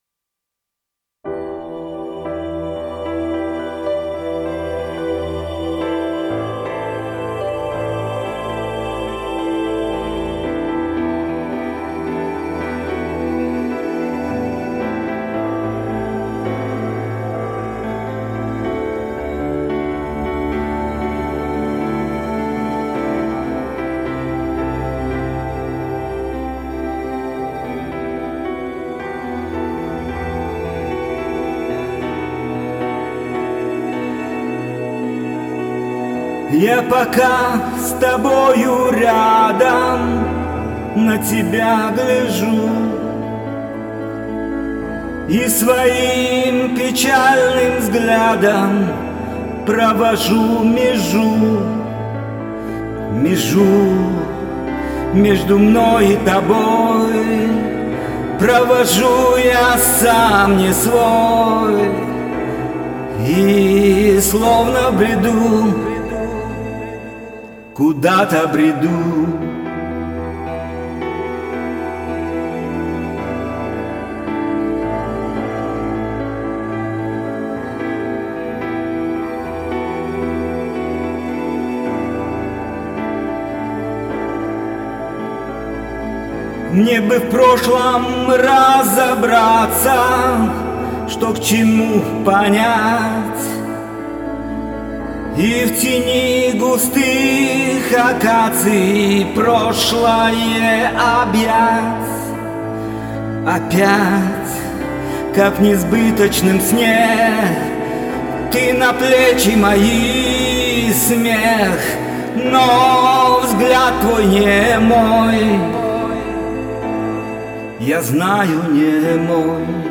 пиано